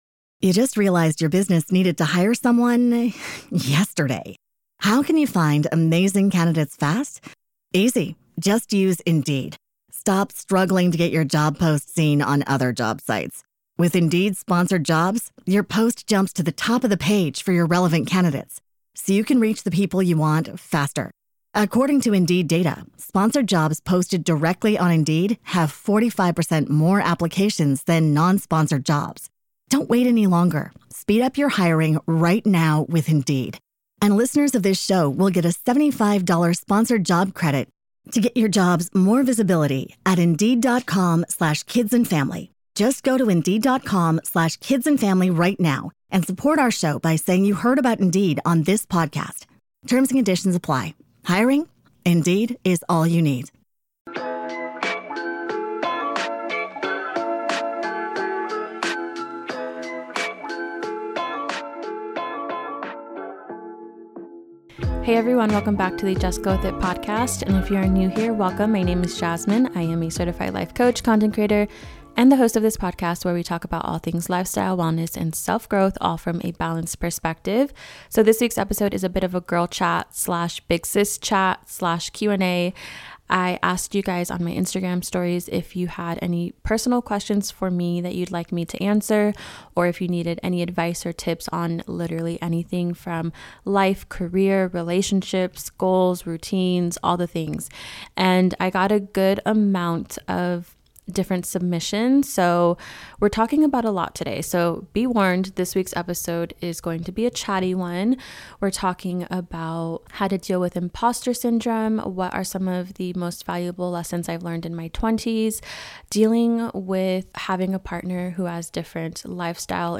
In this episode, we had a candid Q&A session covering relationship advice, imposter syndrome, moving away from home, and lessons from our 20s.